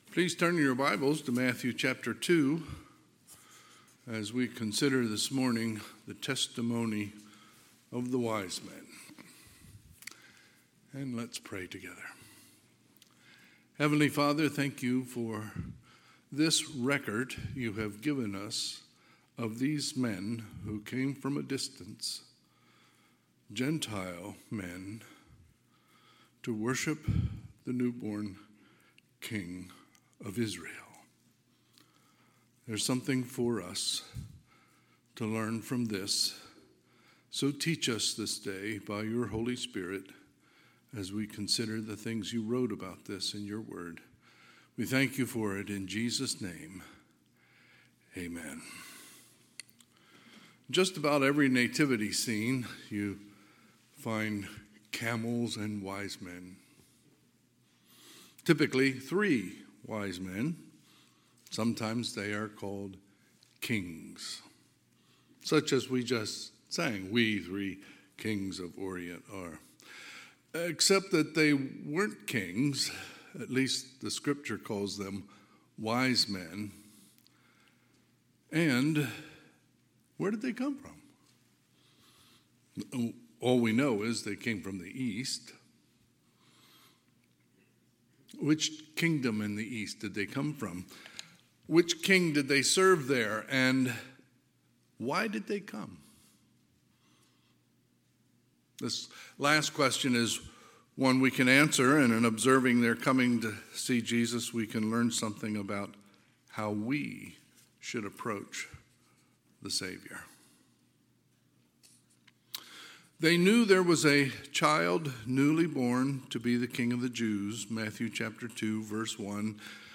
Sermons | North Hills Bible Church | Page 14
Sunday, September 15, 2024 – Sunday PM